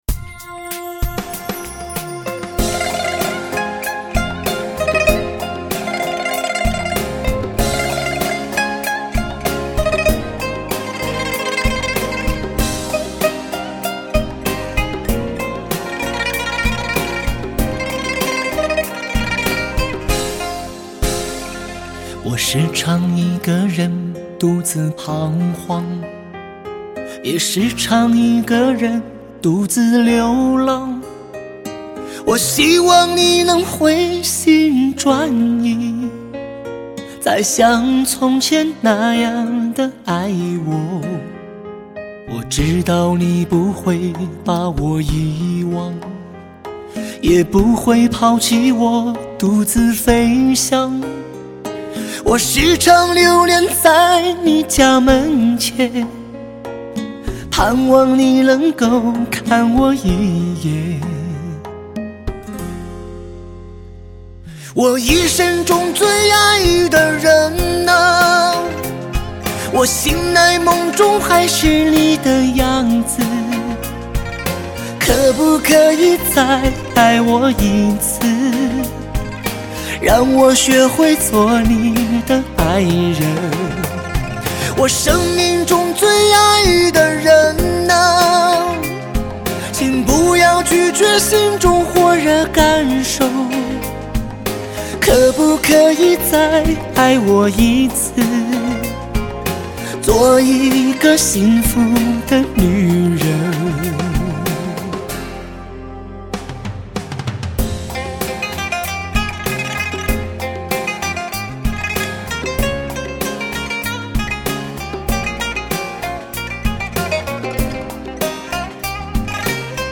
缕缕愁绪的配乐
·14首抒发内心情感的感性之作，剖白男人内心世界